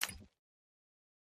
File:ClickSound.mp3